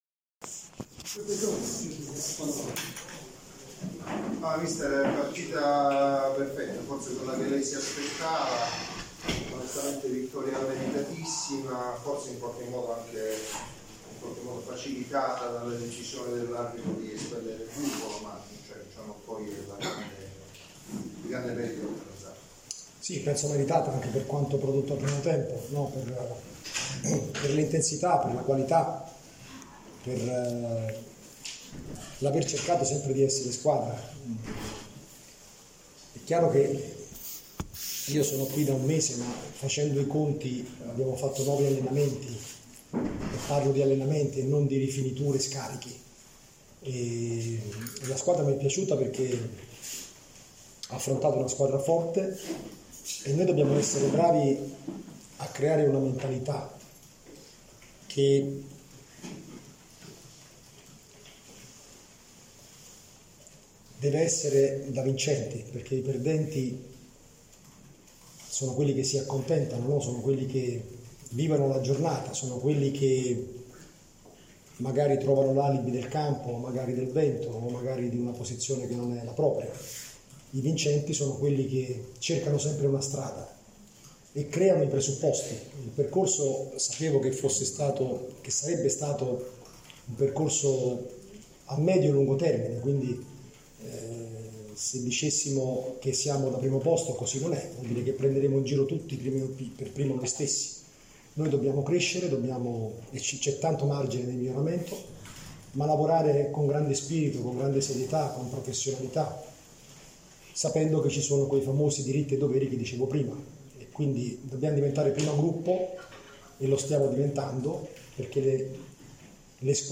in sala stampa a fine partita